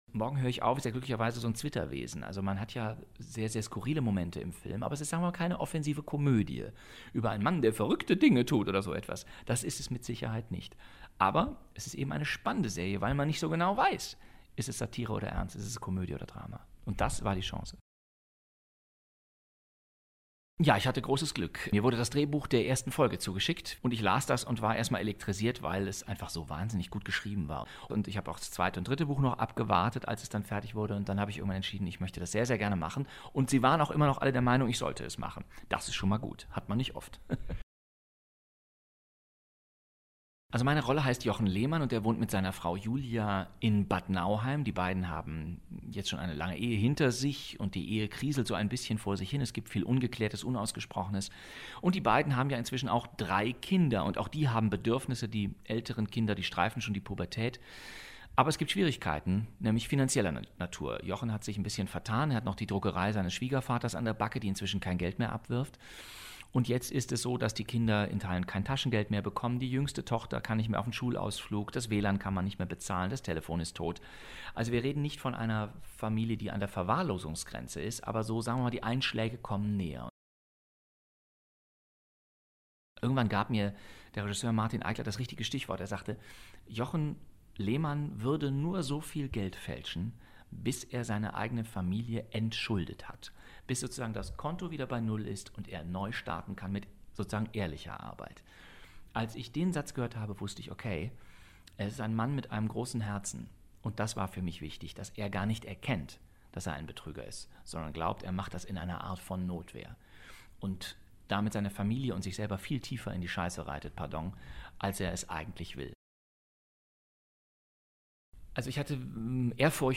Morgen hör ich auf – Bastian Pastewka im Interview
zdf_morgen-hoer-ich-auf-feature-interview-pastewka.mp3